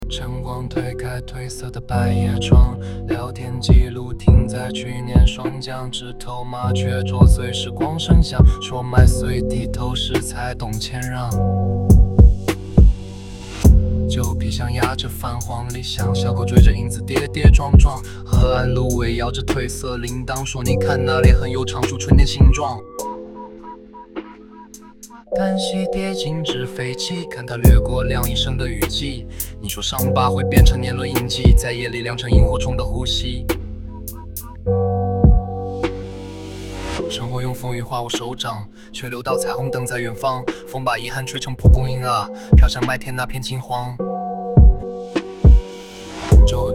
人工智能生成式歌曲